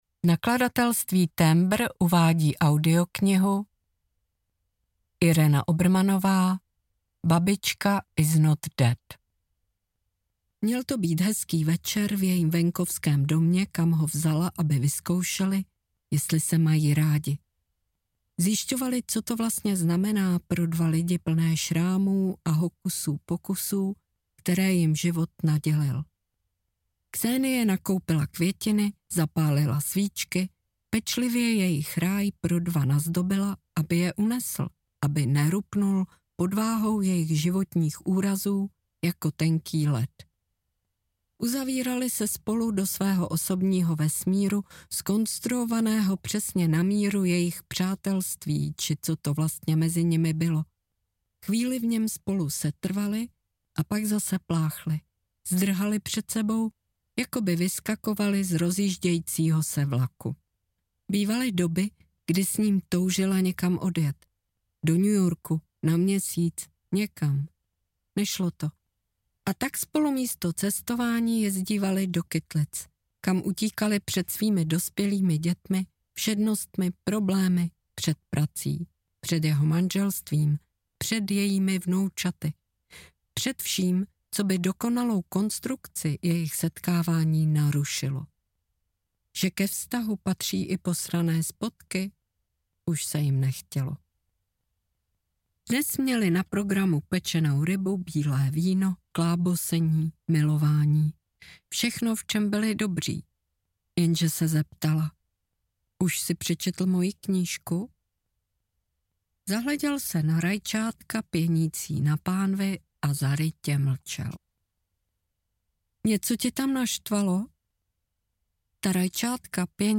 Babička Is Not Dead audiokniha
Ukázka z knihy
• InterpretIrena Obermannová